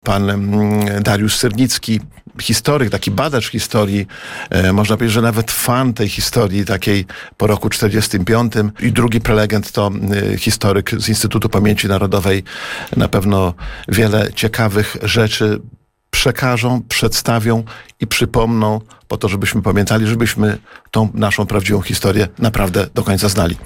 Starosta łomżyński Lech Szabłowski mówił na naszej antenie, że jest to piękna i potrzebna inicjatywa fundacji Iskra Nadziei.